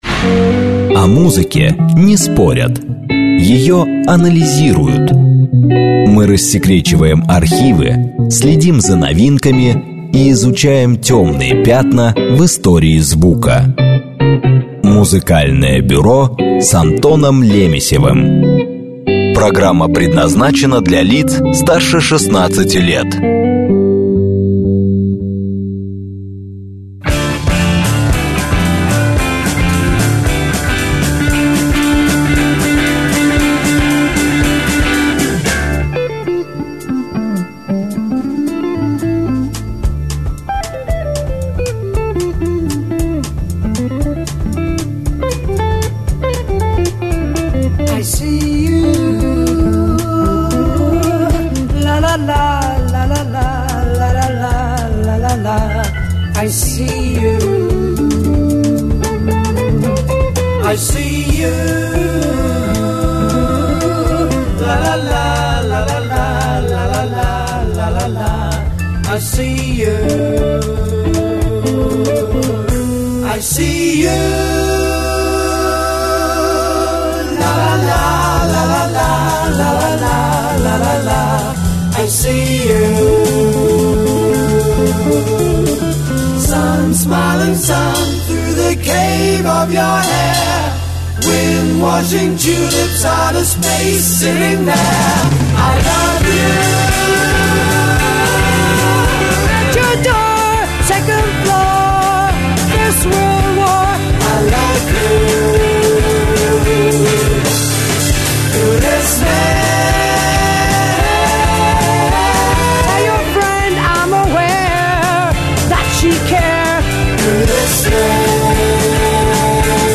Вечер драм-н-бейса